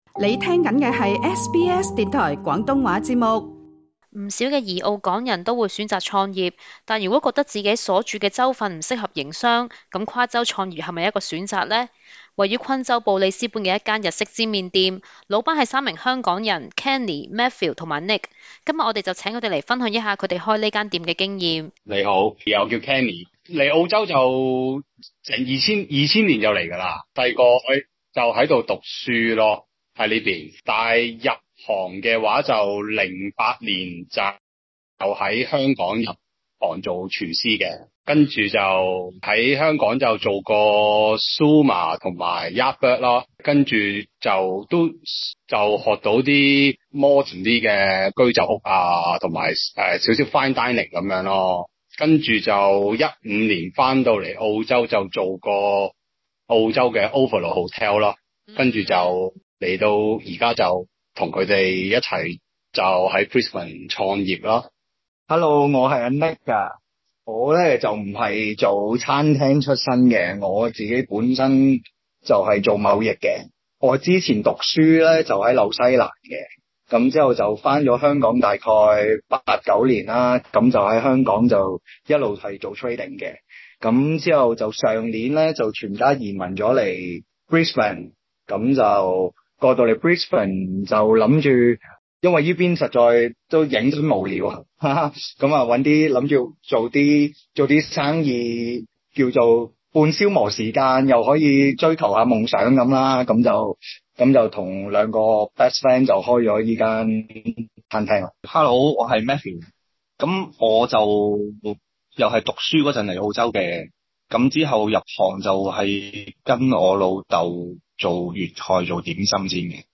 更詳細內容請收聽錄音訪問